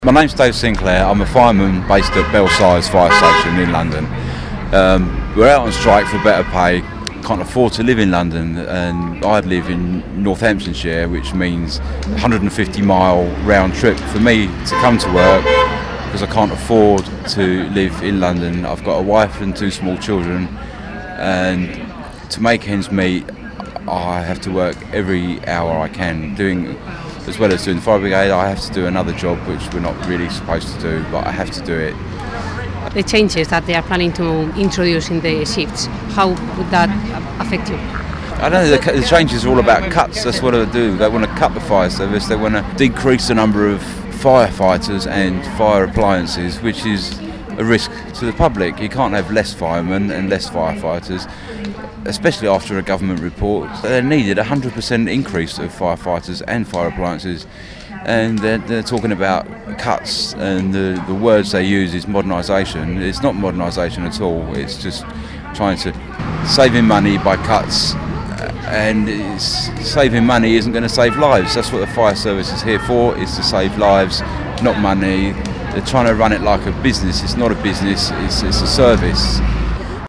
second interview